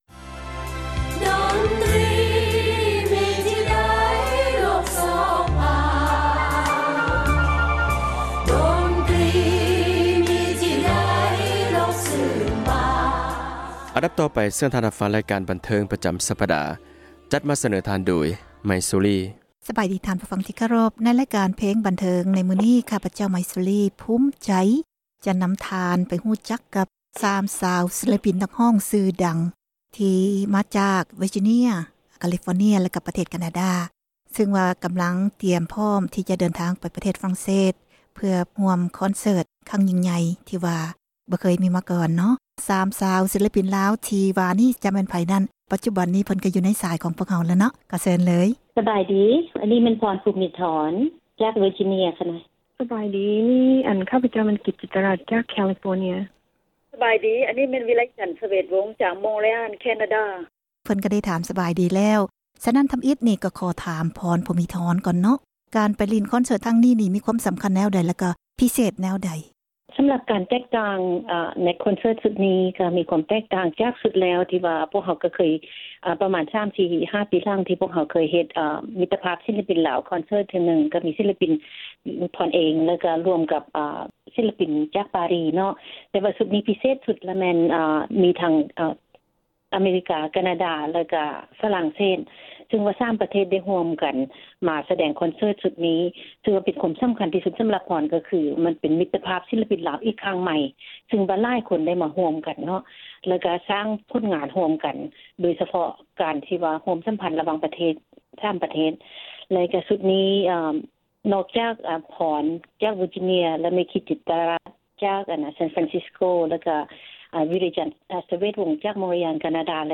ສັມພາດ 3 ນັກຮ້ອງຍິງເຂົ້າຮ່ວມ ຄອນເຊີທ ທີ່ ຝຣັ່ງເສສ